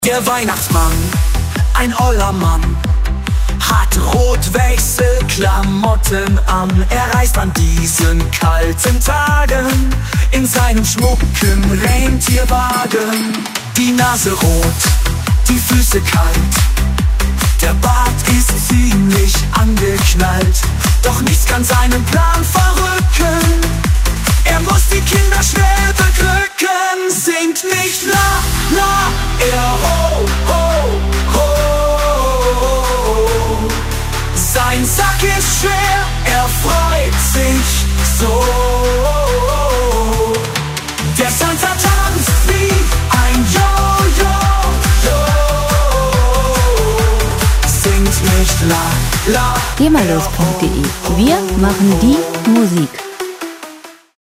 Gemafreie moderne Weihnachtslieder
Musikstil: Schlager-Pop
Tempo: 128 bpm
Tonart: E-Moll
Charakter: übermütig, vergnügt
Instrumentierung: Gesang, Synthesizer